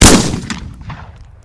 shotgun_shot.wav